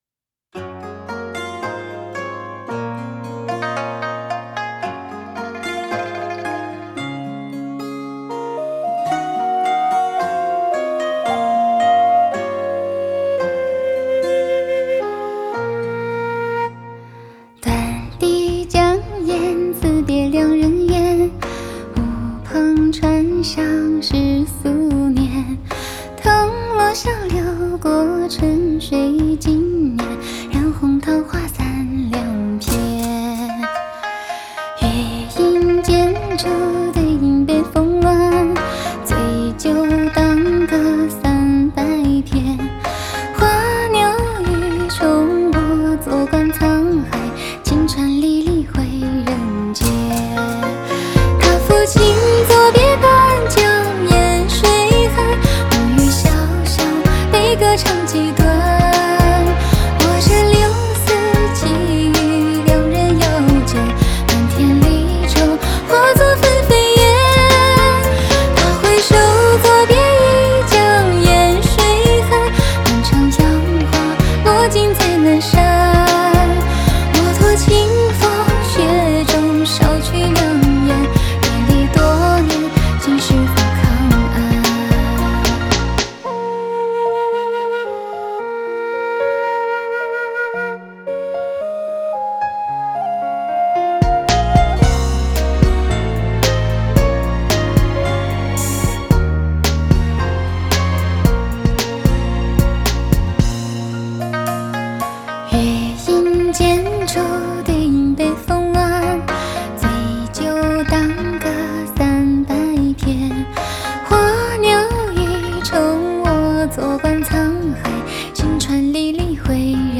Ps：在线试听为压缩音质节选，